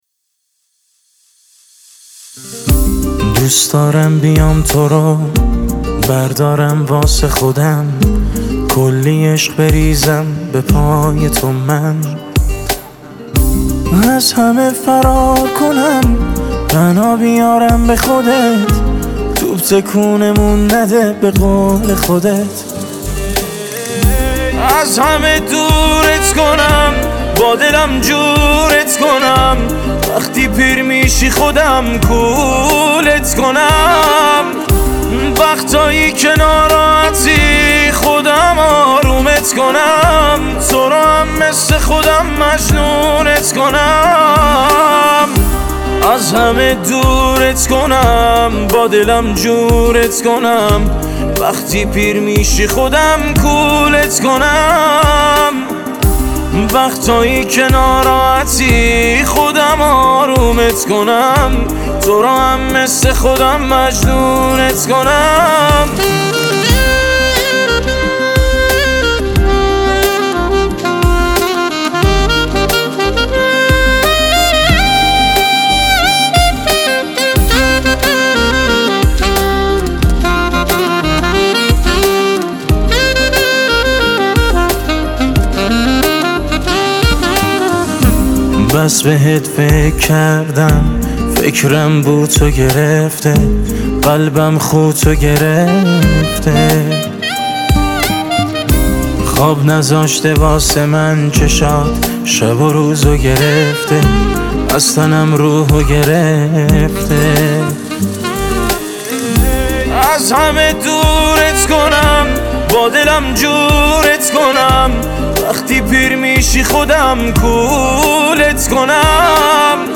پاپ ایرانی